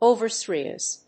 アクセント・音節òver・sérious